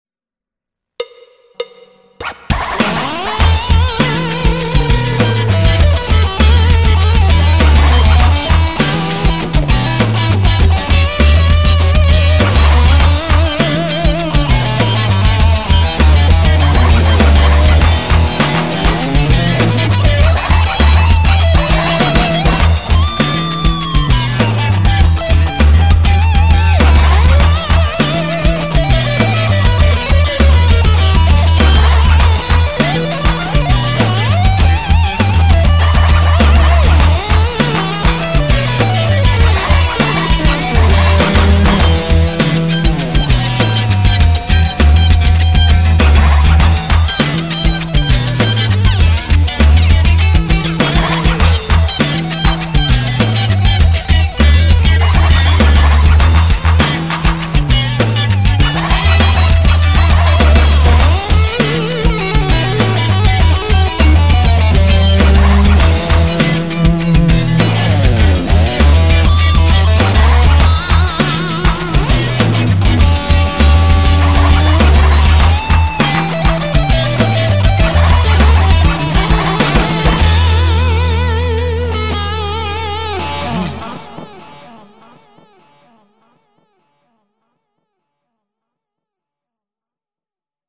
ギター・アドリブ・セッションです（一人だけど）。